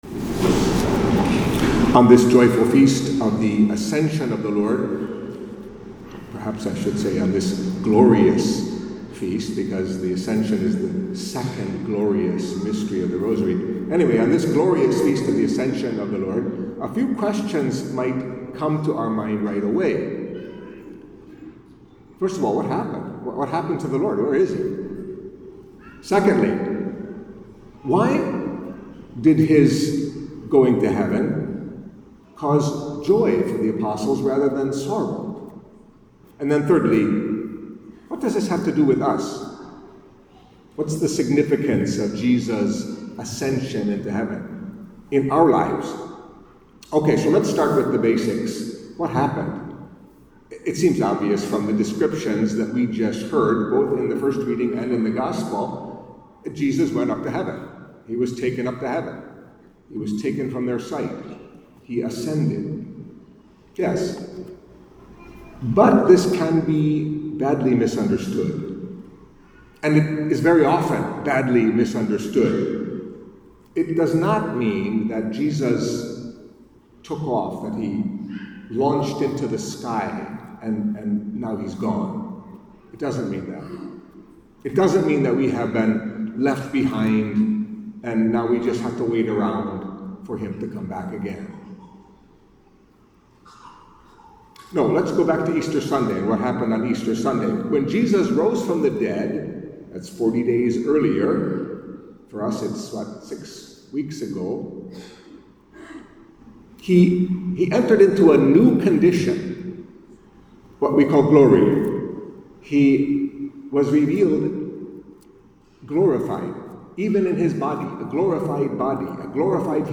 Catholic Mass homily for Solemnity of the Ascension of the Lord